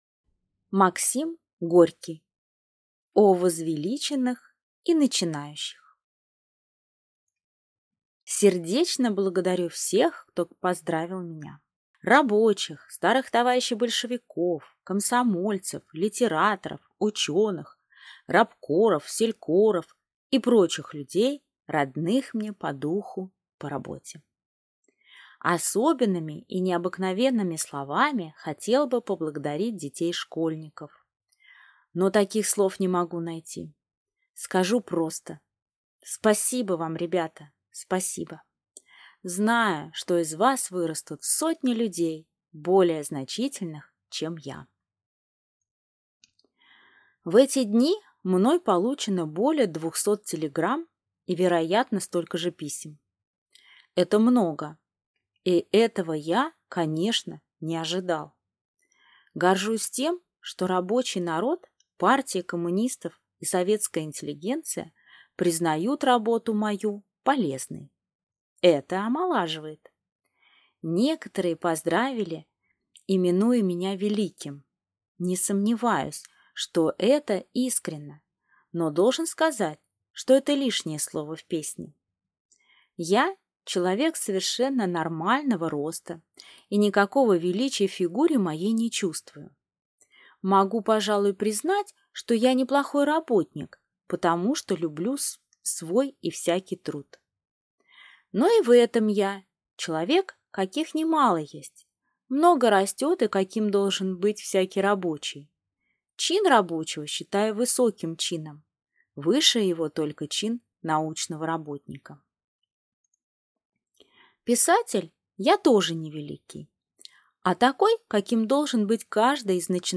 Аудиокнига О возвеличенных и «начинающих» | Библиотека аудиокниг